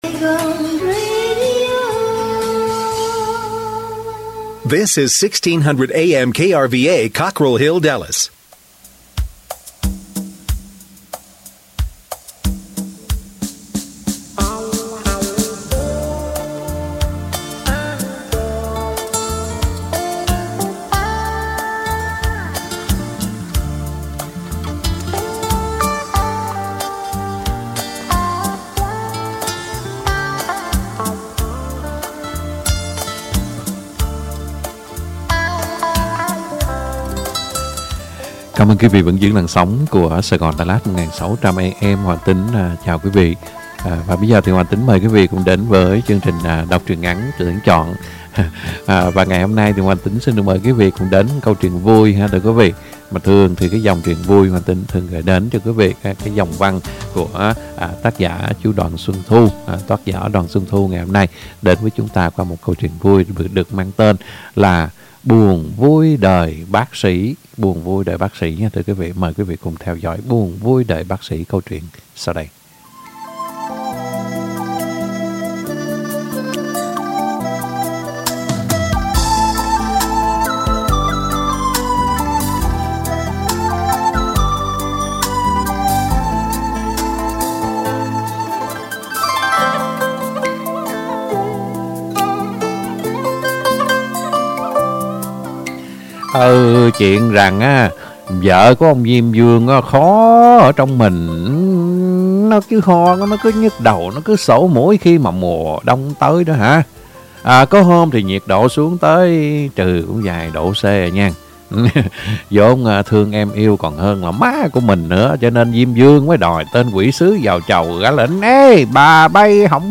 Đọc truyện ngắn - Buồn vui đời bác sĩ !!!